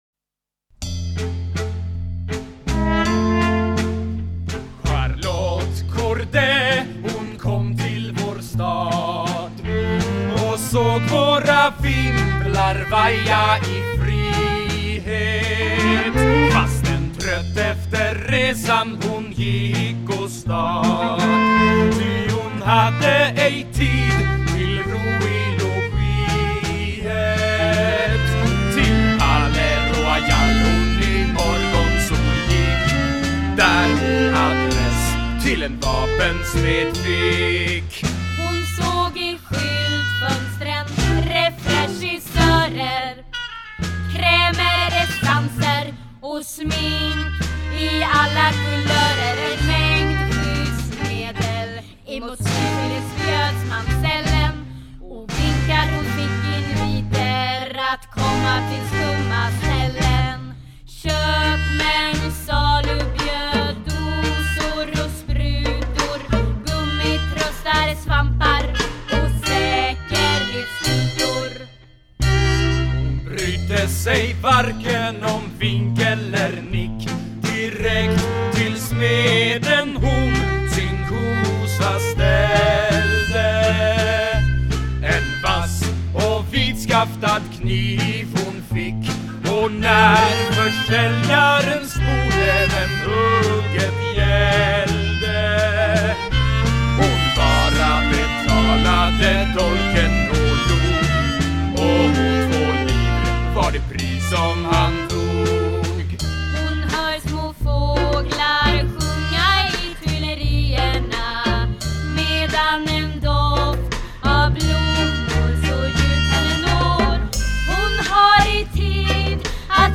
Visa och pantomim om Charlotte Cordays ankomst till Paris